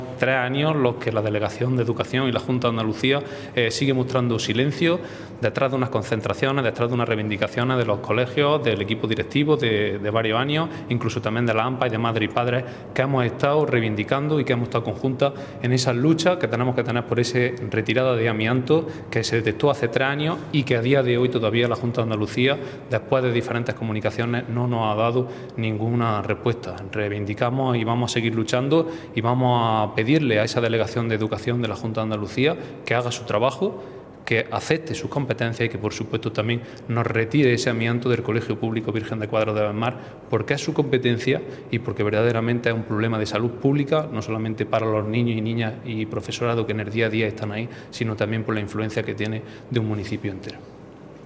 Cortes de sonido
Enrique-Carreras-amianto.mp3